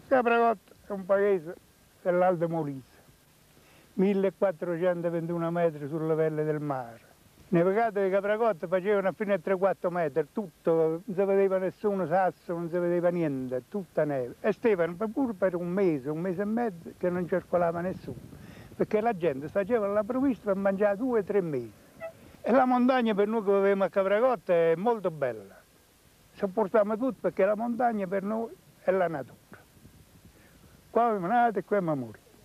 spezzone dell'intervista resa ai microfoni Rai durante il programma "Bellitalia" del 1995.